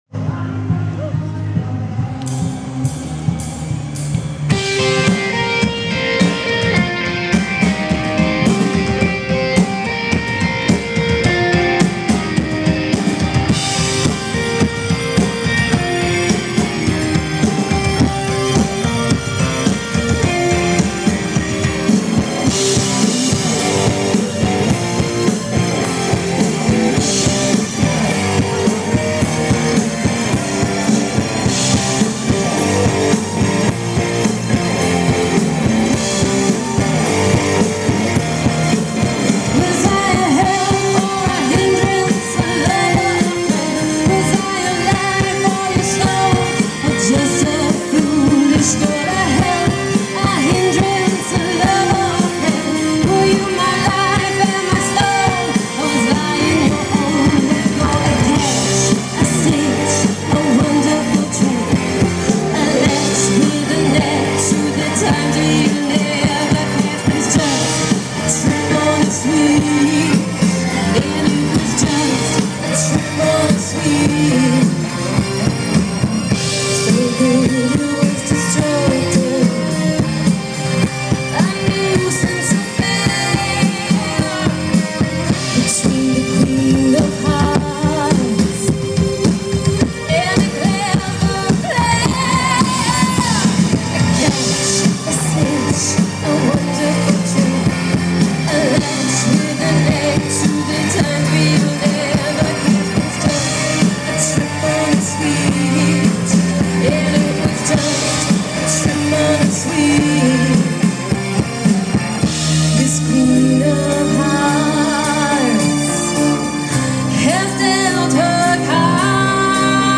Molson main stage